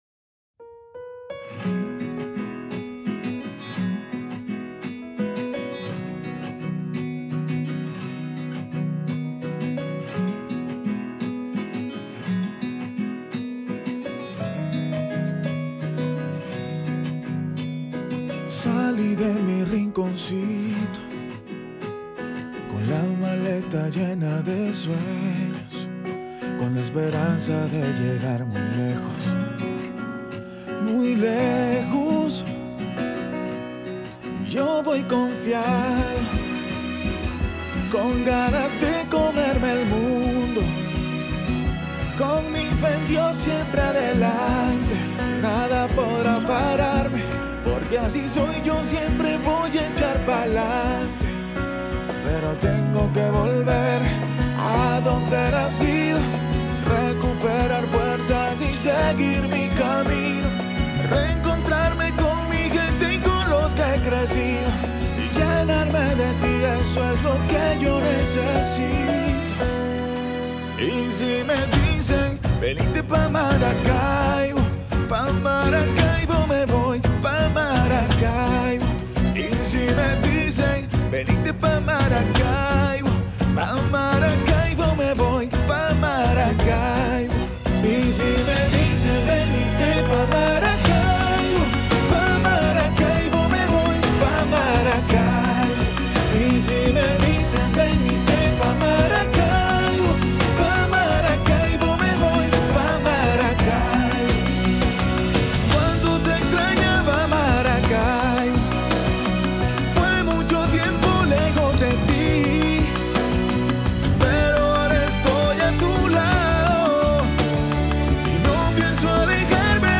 Música Original: